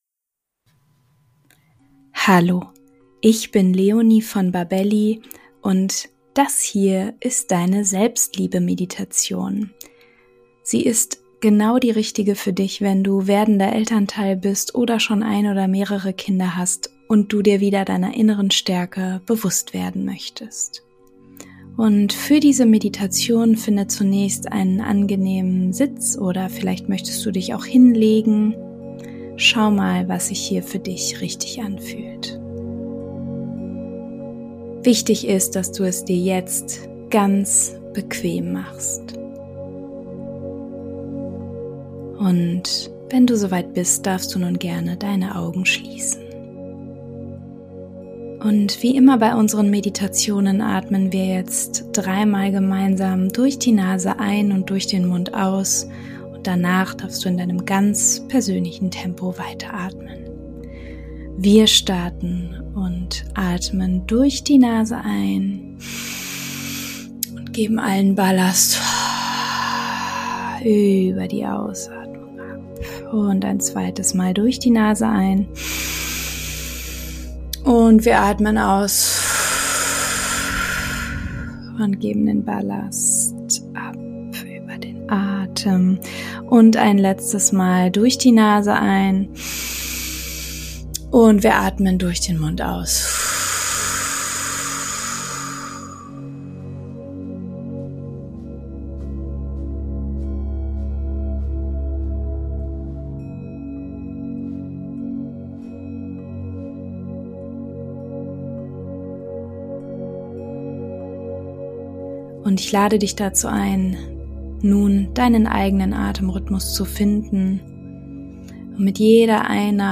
Mit dieser Meditation kannst du deine Selbstliebe stärken und dich selbst als das Wunder erkennen, das du bist.